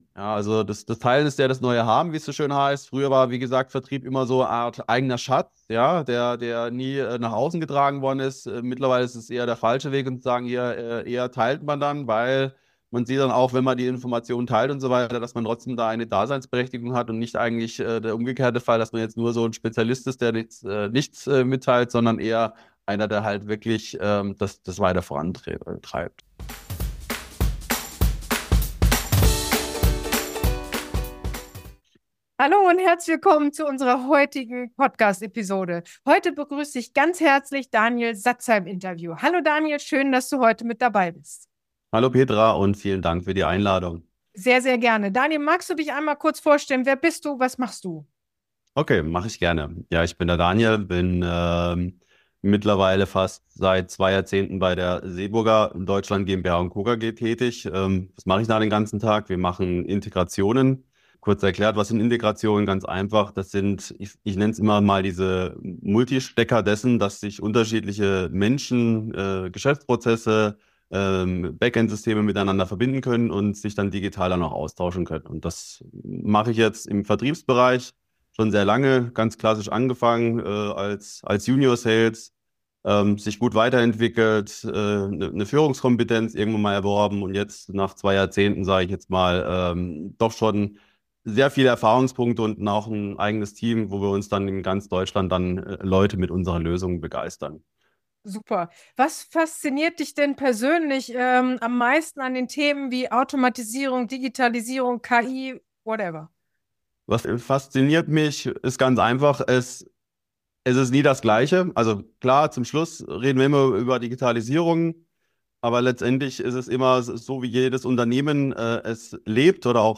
Zwischen Automatisierung und Akquise: Wohin entwickelt sich der Vertrieb? | Interview